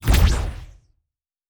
pgs/Assets/Audio/Sci-Fi Sounds/Weapons/Sci Fi Explosion 07.wav at master
Sci Fi Explosion 07.wav